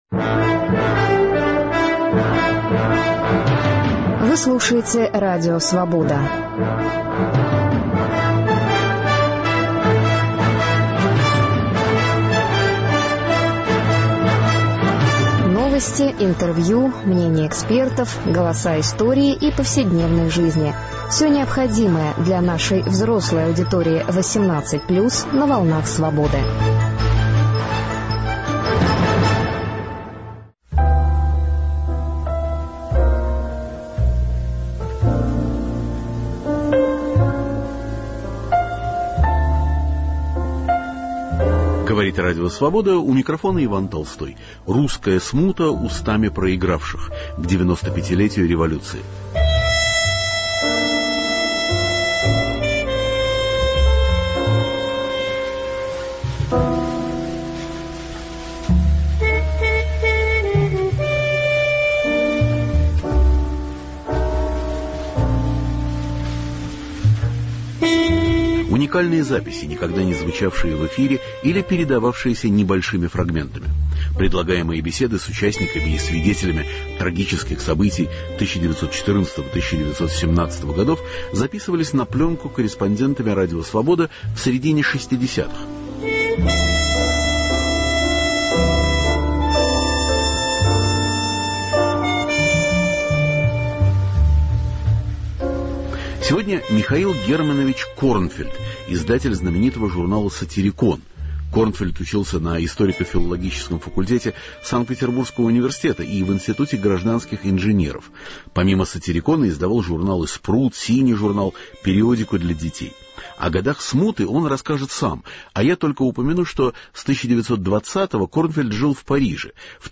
Архивные записи.